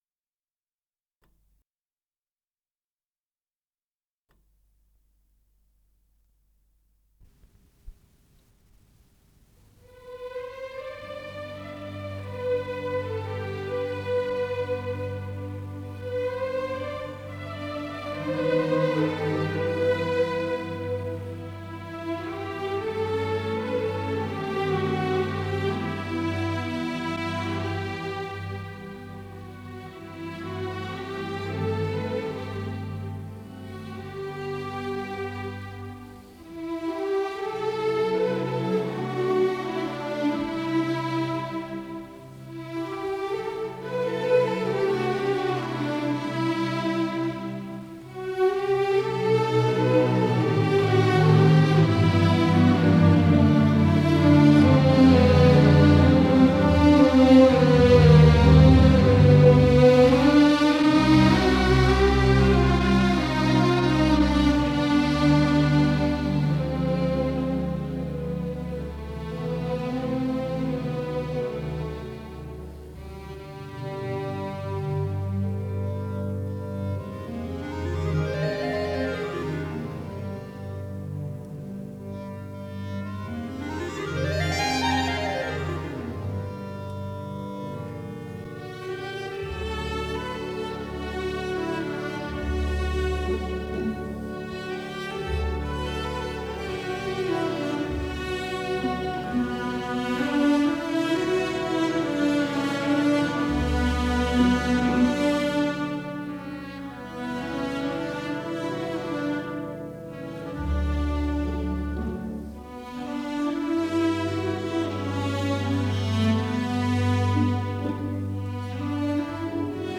Исполнитель: Симфонический оркестр СССР
Симфоническая сюита
ми мажор